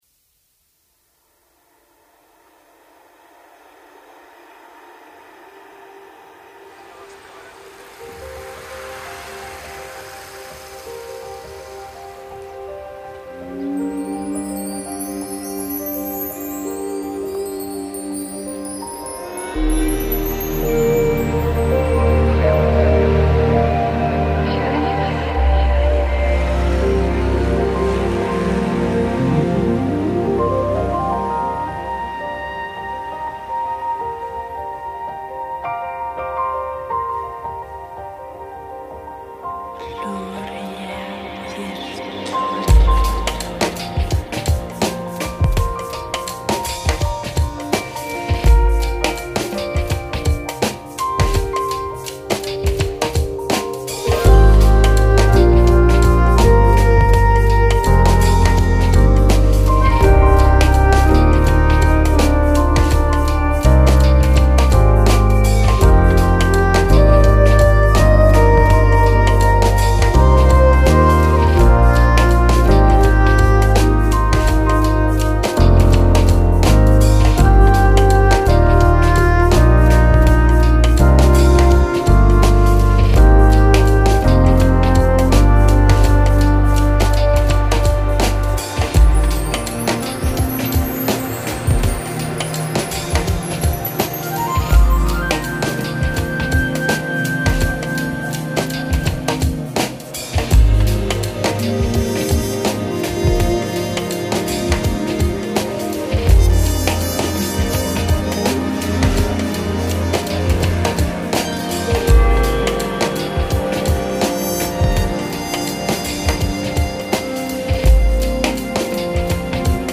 Featuring: Evolution Rosewood Grand, Cherry Electric Bass, Lap Steel Guitar, Passion Flute, Evolution Acoustic Guitar - Steel Strings, Angelic Zither, Mark Tree, Rainstick
This song has tons of awesome ambient elements going on. The way the form of the song builds and then subsides is masterfully done.